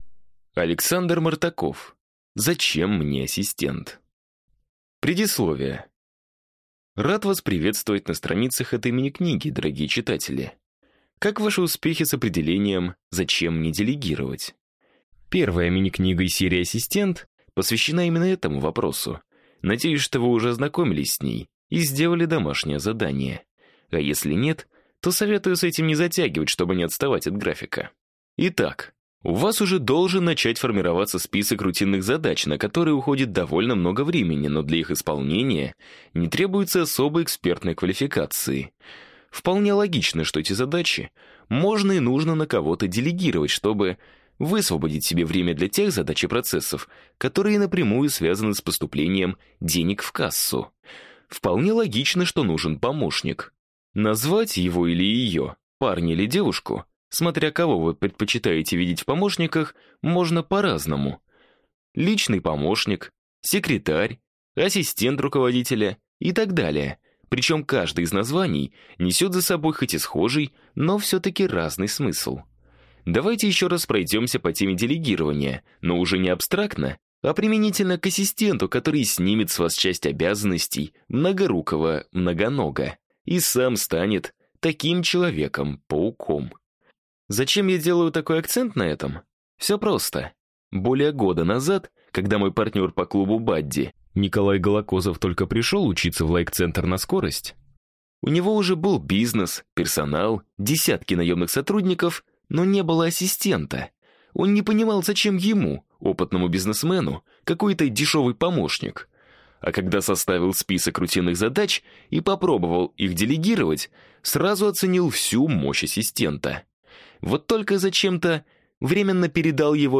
Аудиокнига Зачем мне ассистент?